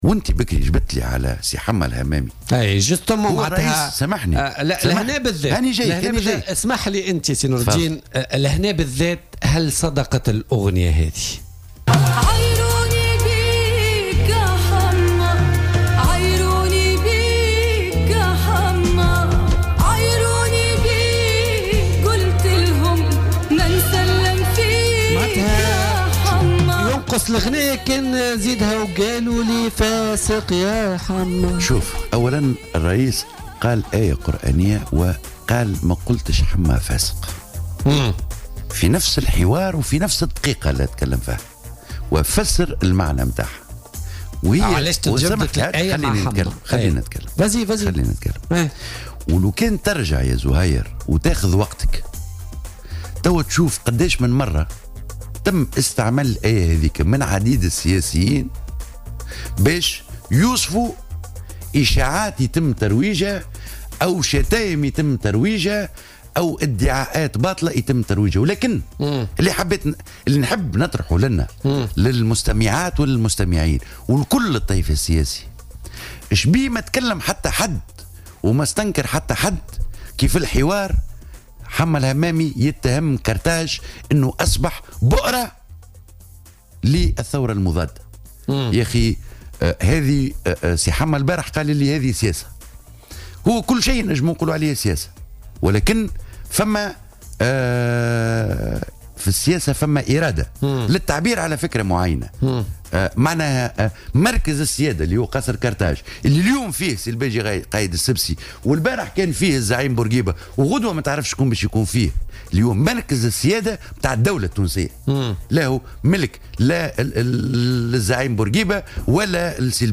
قال المستشار الأول لرئيس الجمهورية نور الدين بن تيشة خلال استضافته اليوم في "بوليتيكا" إن رئيس الجمهورية الباجي قائد السبسي لم يصف الناطق الرسمي باسم الجبهة الشعبية حمة الهمامي بالفاسق، وإنما ذكر آية قرآنية استعملت من طرف عديد السياسيين لوصف إشاعات آو ادعاءات وشتائم يتم ترويجها.